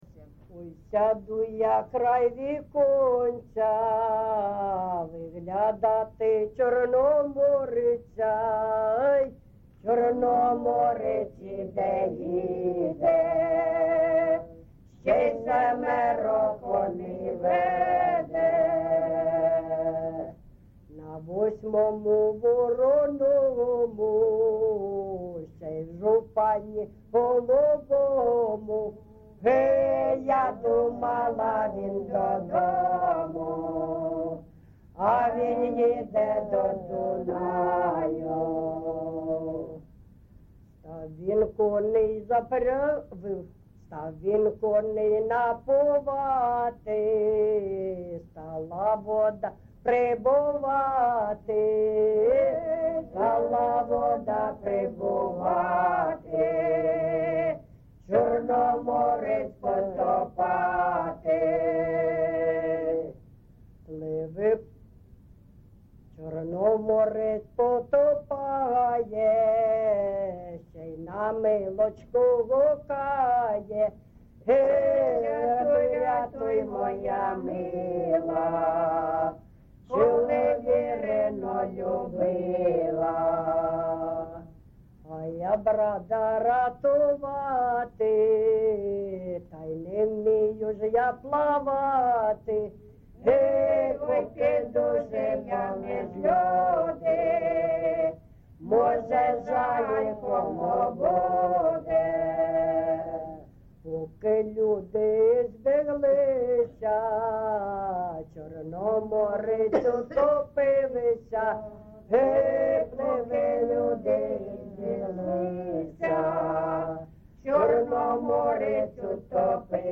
ЖанрПісні з особистого та родинного життя, Козацькі